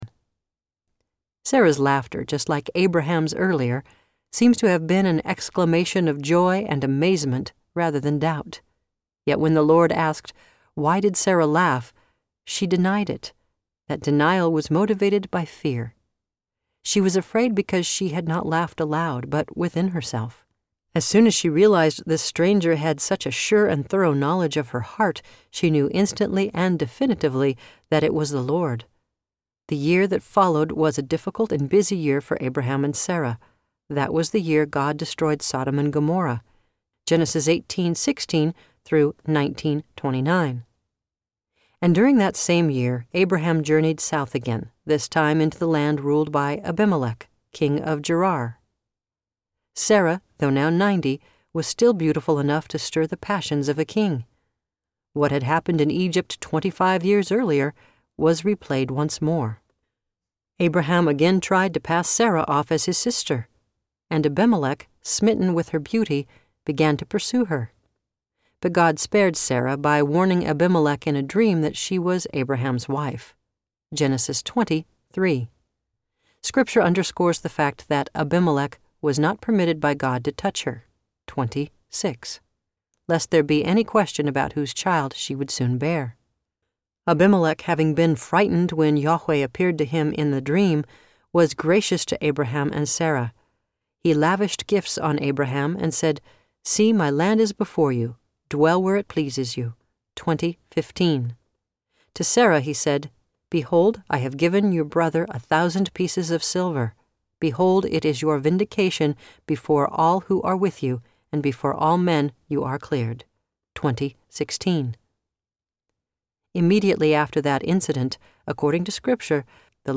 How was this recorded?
7 Hrs. – Unabridged